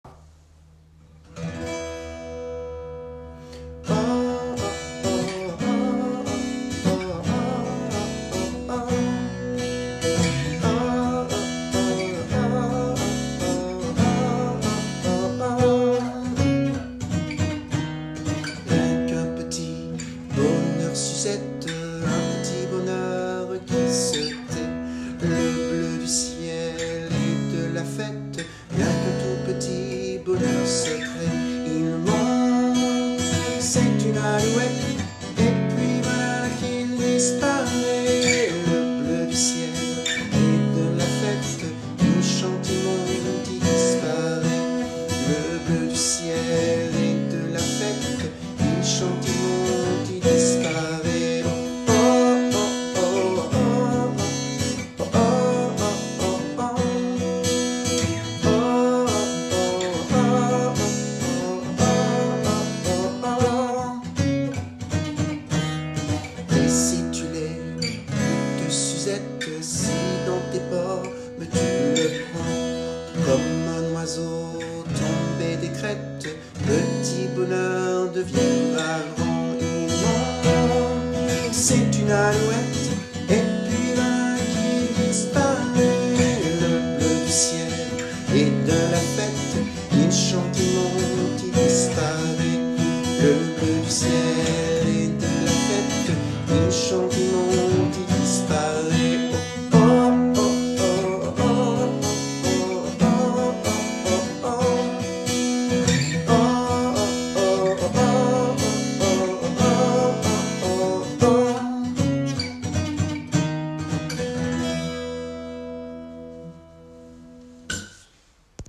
une poésie en chanson : Au petit bonheur de Norge.